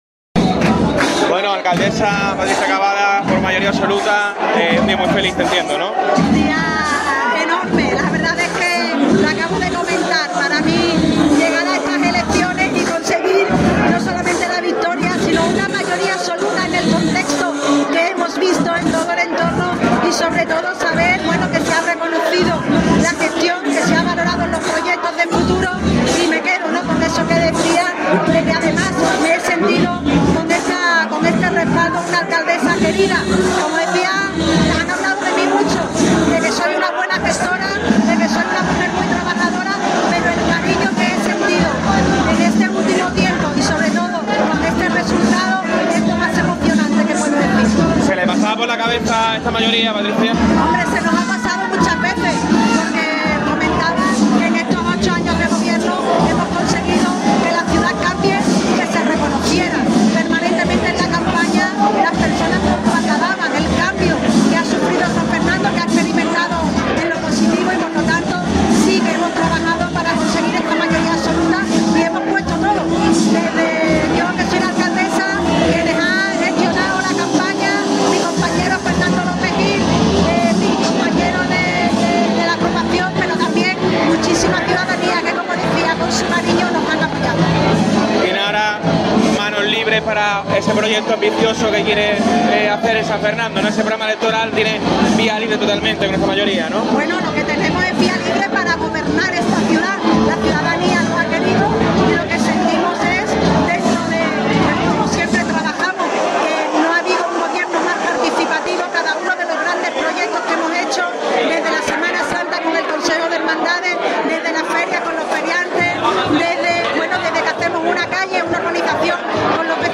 Patricia Cavada celebra su victoria en las elecciones